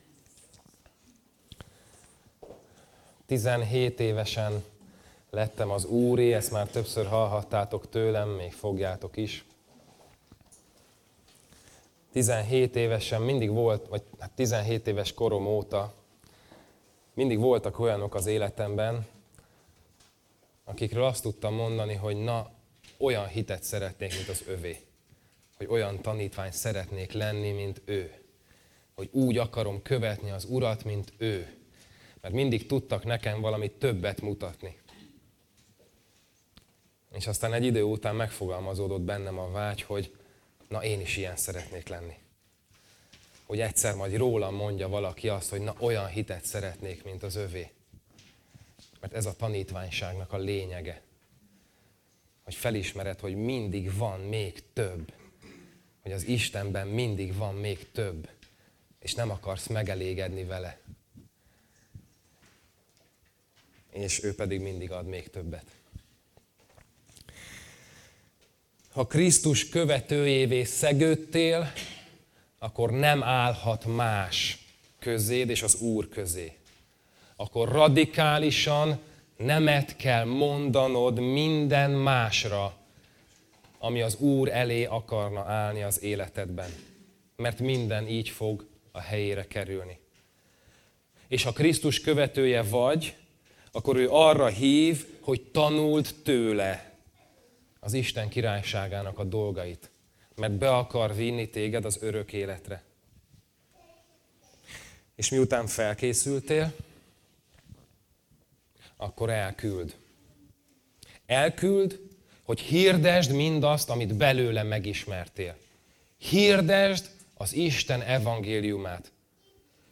Passage: Máté 10, 7, 16 Service Type: Tanítás